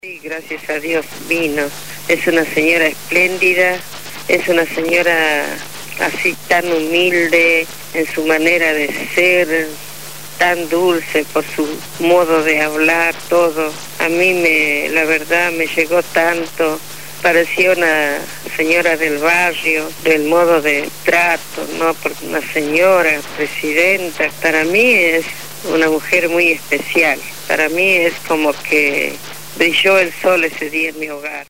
fue entrevistada en Radio Gráfica FM 89.3 durante la emisión del programa Punto de Partida